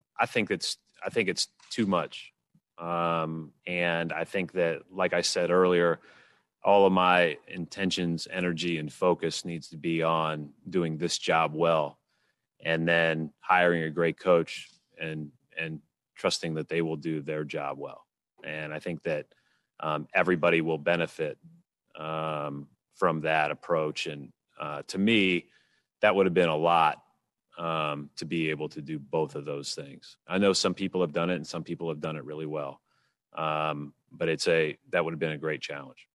When he sat down with media to comment on his new role, he explained how he did not want to keep both jobs as he wants to put all of his focus into rebuilding the depleted team.